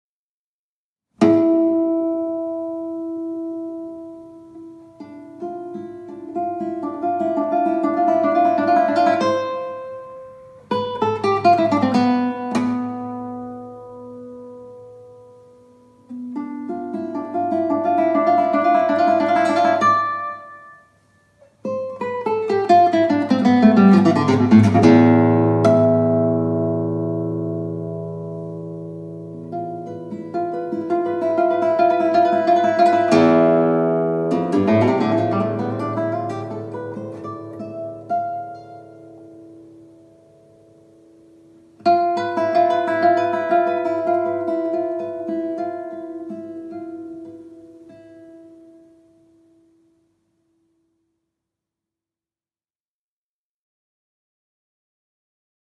Allegretto   0:39